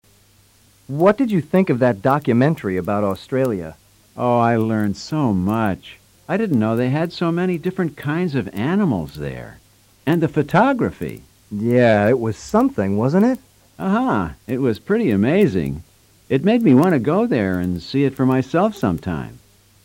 Cuatro personas opinan sobre libros y cine.